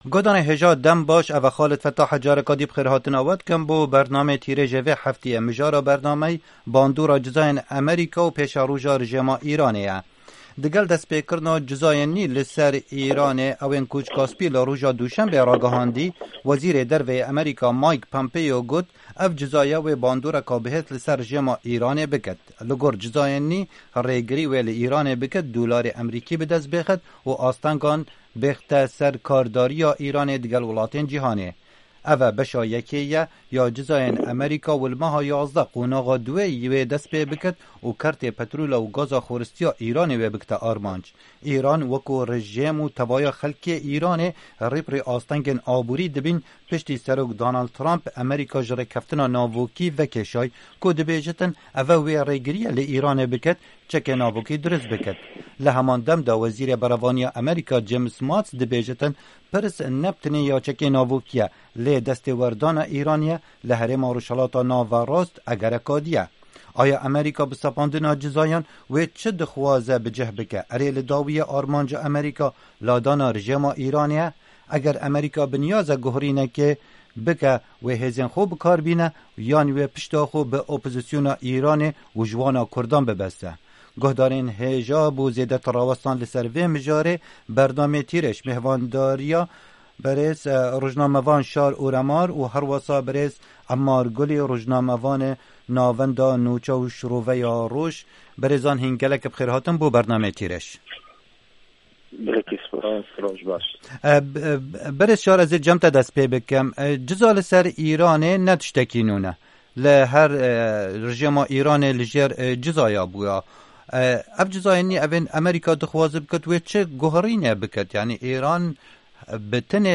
Bernama Tîrêj pirsa sizayên nû yên Amerîka li dijî Îranê û siberoja wî welatî bi mêhvanên bernamê re gotûbêj dike.